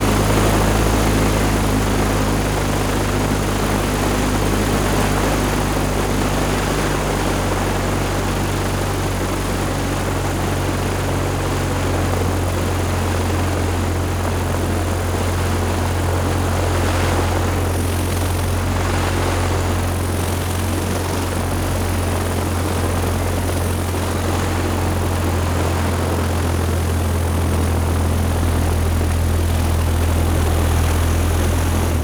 Propeller_Plane-44k.wav